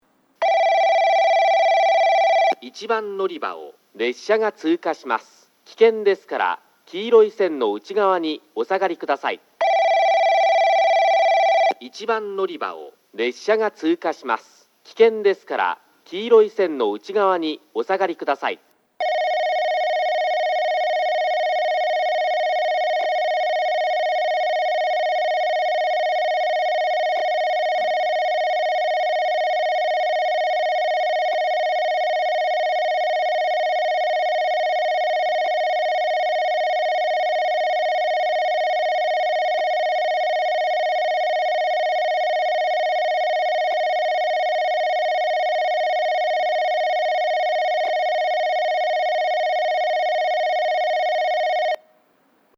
1番のりば通過放送　男声   放送はJACROS簡易詳細型です。
なお一部の音声はベル部分は省略しておりますのでご了承ください。
スピーカーはJVCラインアレイですが、設置数が多いので収録が行いやすくなっています。